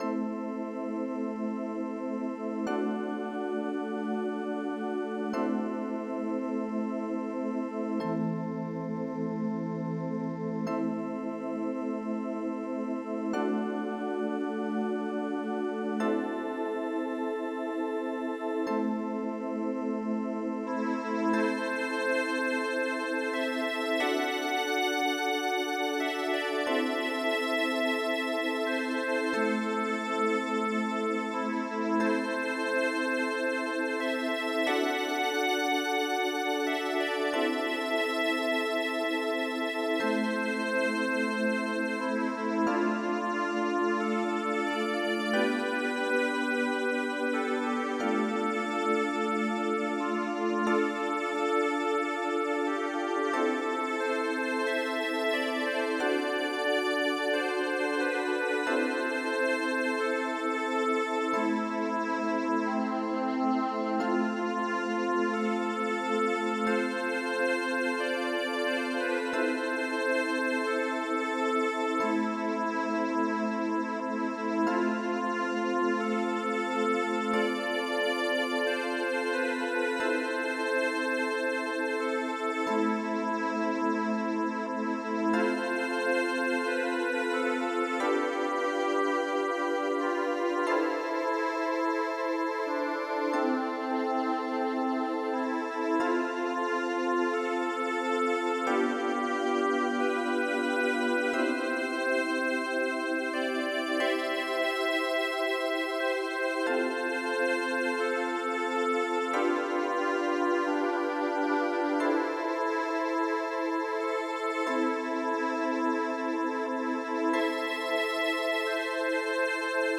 Extended MIDI